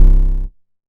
MoogAgressUp A.WAV